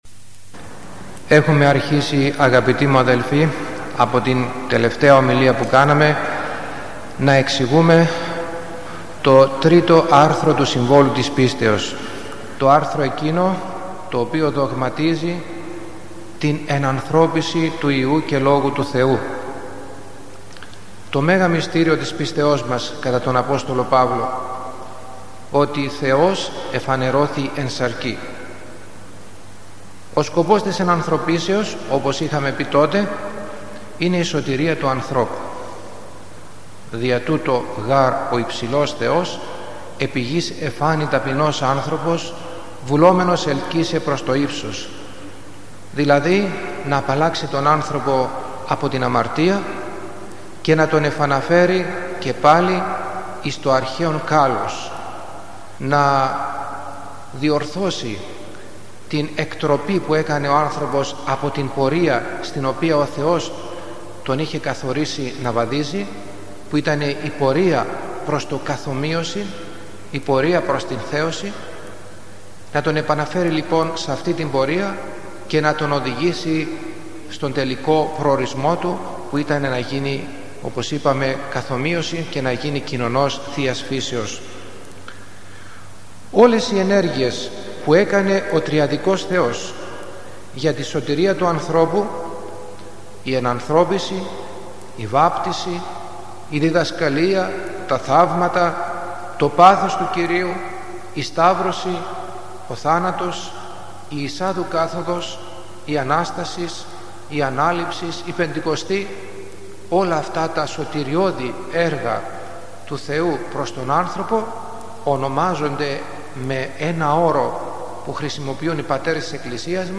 Περί της θείας ενανθρωπήσεως (Β’) – Ομιλία στο Σύμβολο της Πίστεως